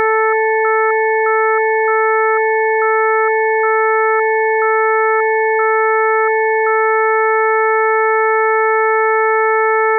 In the next example, we variably suppress the 1320 Hz harmonic here and there, making it appear and disappear from the sound (Figure 1c). Now the 1320 Hz tone stands out as a distinct item, clearly separated from the remaining four stationary tones that form the chord and thus two separate streams are perceived. When listening to the last 3 seconds, you will probably perceive how the tone fuses again within the consonant chord once it becomes again stationary again.
Figure 1c: Chord 440 with time-varying 1320 Hz time-varying tone